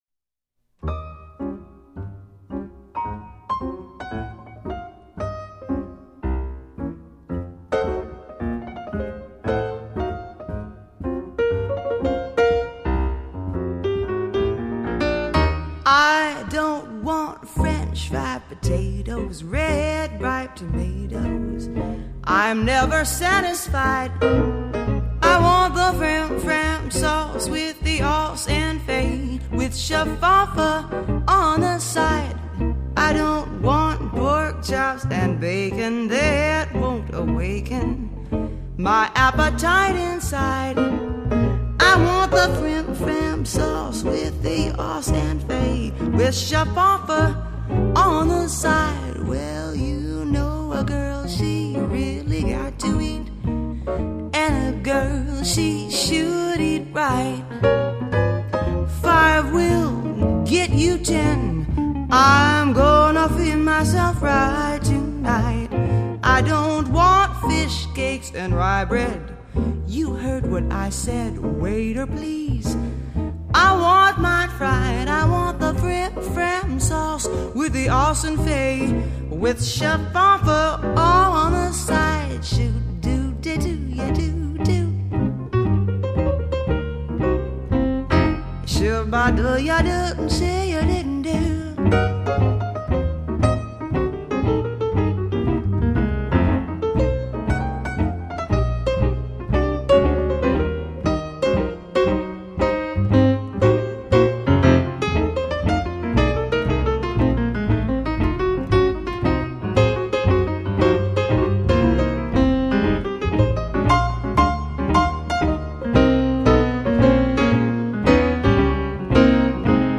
音乐类型：爵士乐
而她的嗓音就像是加了蜂密的威士忌，一醉却令人无法抗拒。